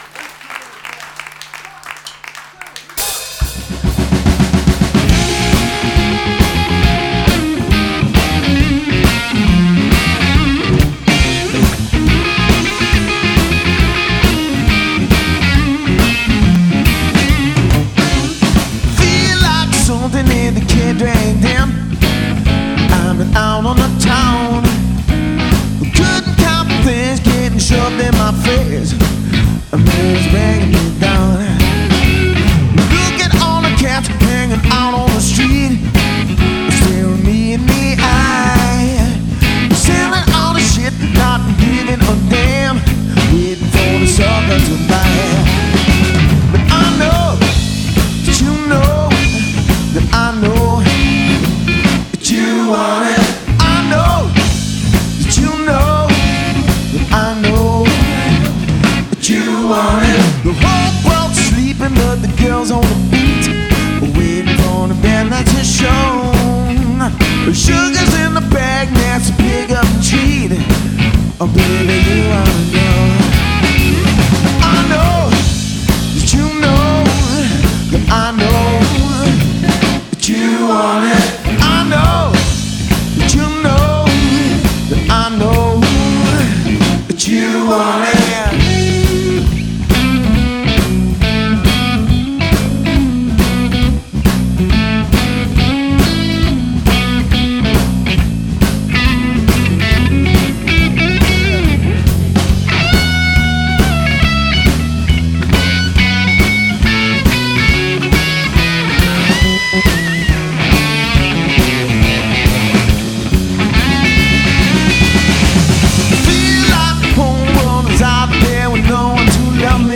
straight ahead classic 70’s Rock